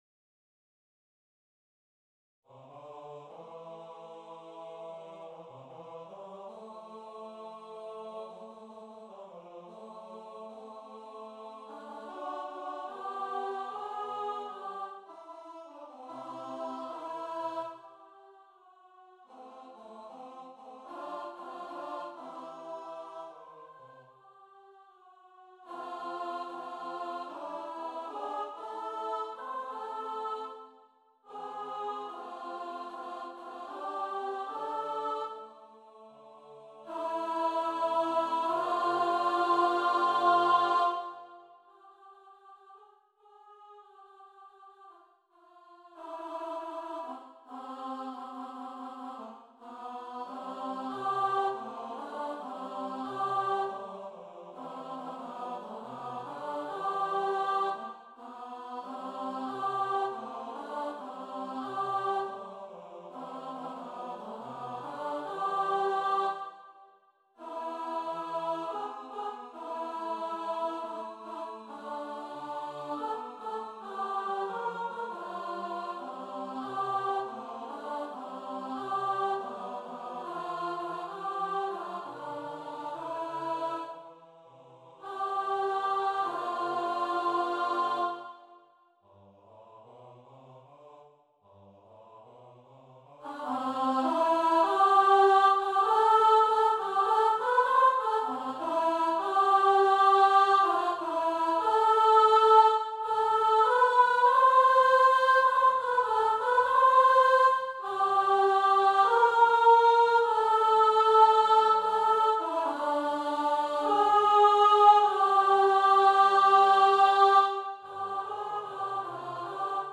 TV-Theme-Medley-Alto | Ipswich Hospital Community Choir
TV-Theme-Medley-Alto.mp3